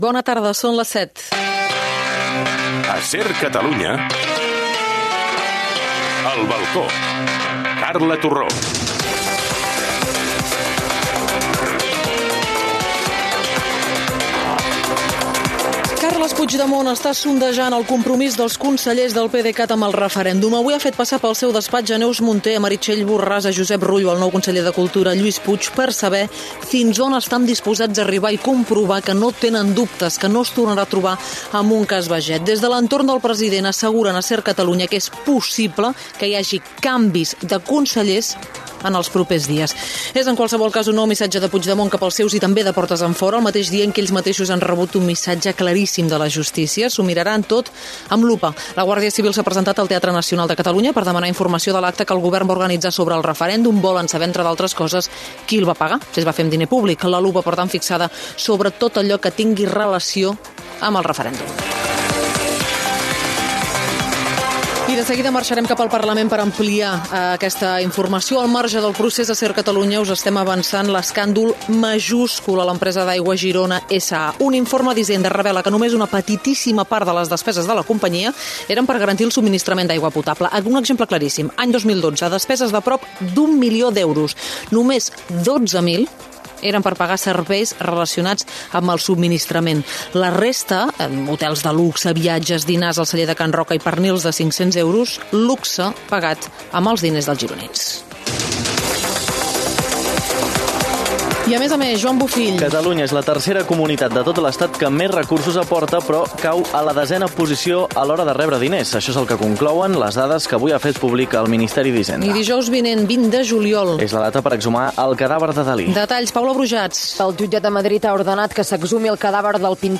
d9d3922aac6a95b4cc787e3aa923173b6b894cda.mp3 Títol SER Catalunya Emissora SER Catalunya Cadena SER Titularitat Privada estatal Nom programa El balcó (SER) Descripció Indicatiu de SER Catalunya i del programa, sumari informatiu.
En el marc de la causa del Jutjat d’Instrucció número 13, la Guàrdia Civil es presenta al Teatre Nacional de Catalunya a demanar la documentació relacionada amb l’acte del dia 4 de juliol on Junts pel Sí van presentar el referèndum. Declaracions de Lluís Llach de Junts pel Sí i de Xavier García Albiol del Partido Popular.
Info-entreteniment